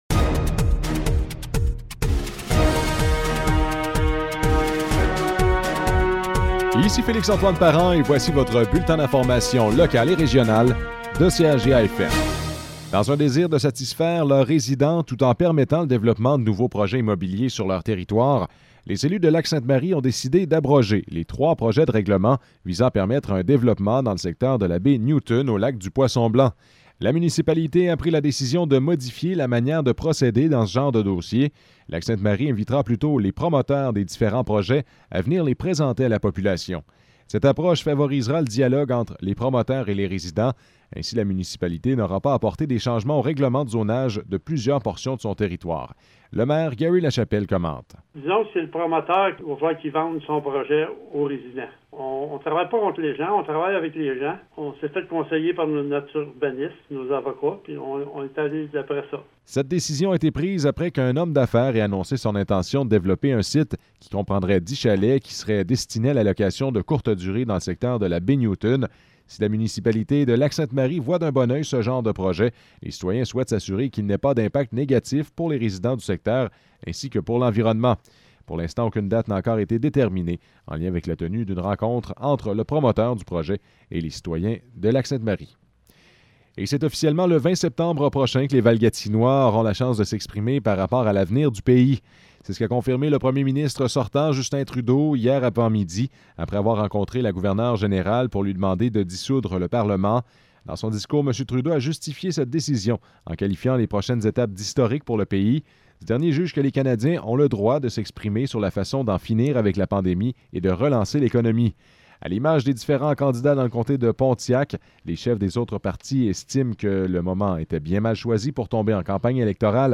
Nouvelles locales - 23 juillet 2021 - 12 h